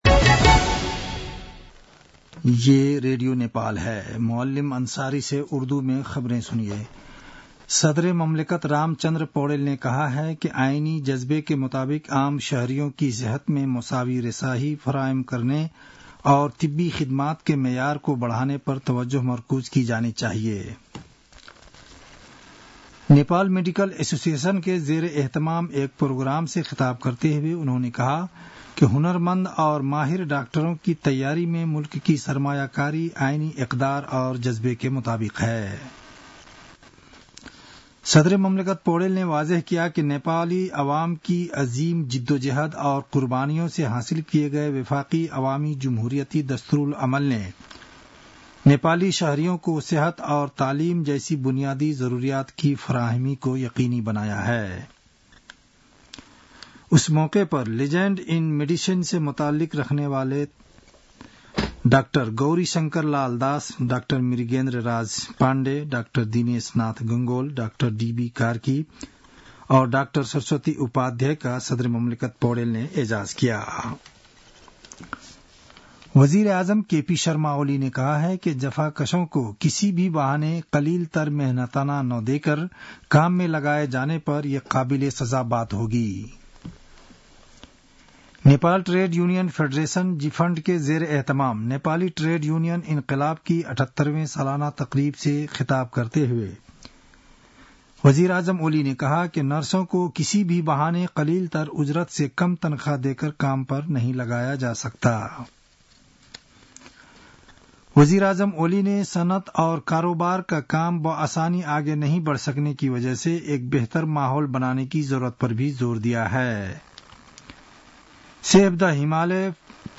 उर्दु भाषामा समाचार : २१ फागुन , २०८१
URDU-NEWS-11-20.mp3